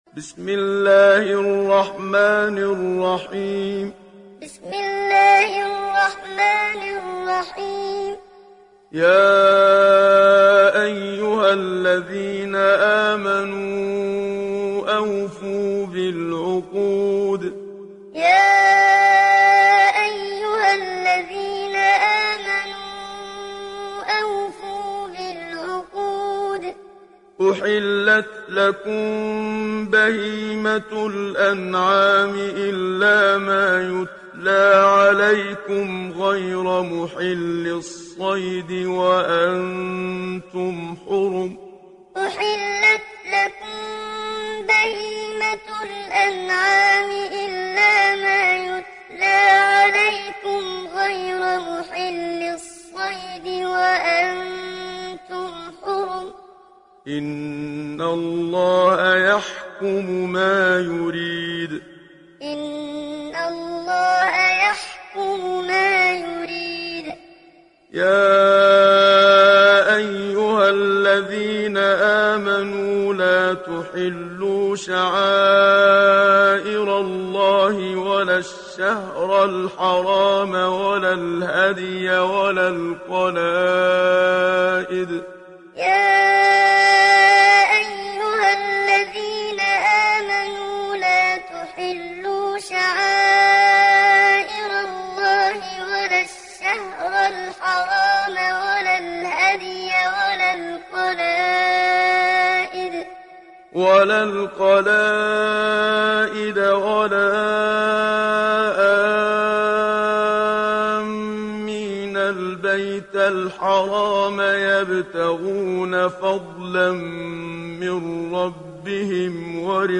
সূরা আল-মায়েদাহ্ mp3 ডাউনলোড Muhammad Siddiq Minshawi Muallim (উপন্যাস Hafs)
Muallim